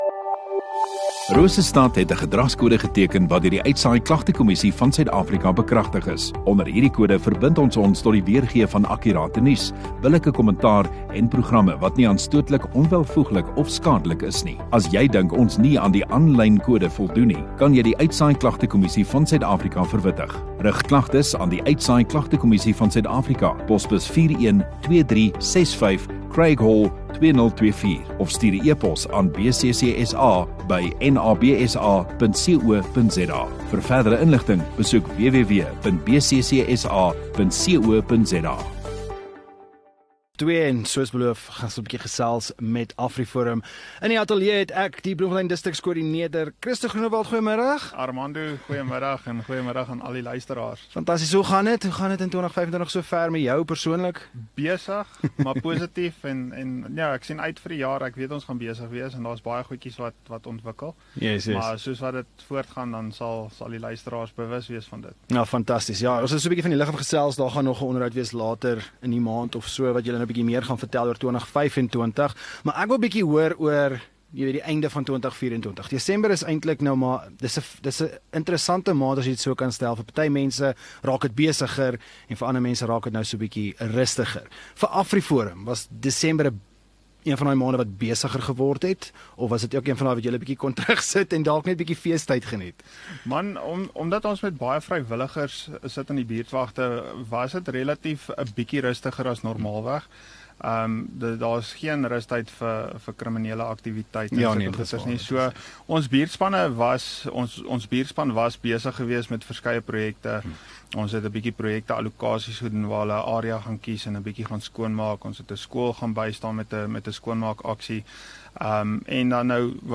Radio Rosestad View Promo Continue Radio Rosestad Install Gemeenskap Onderhoude 14 Jan AfriForum